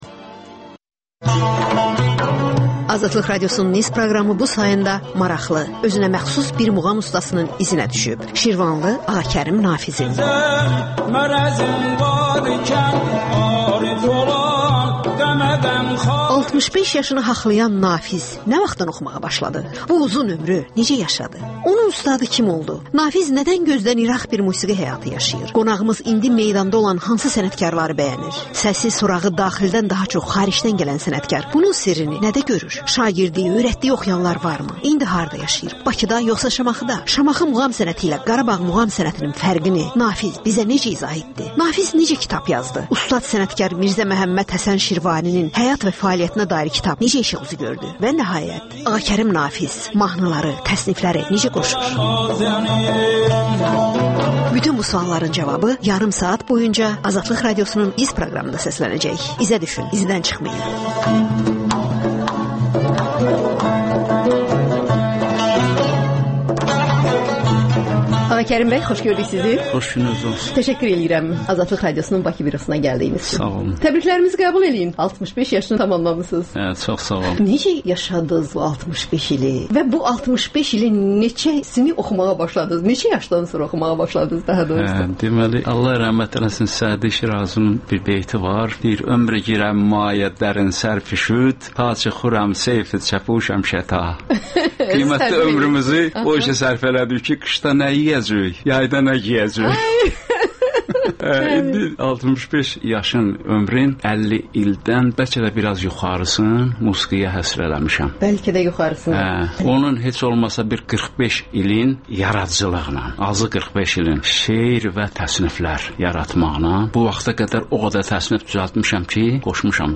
İz - Səsi Şirvan xalçasına bənzədilən xanəndə...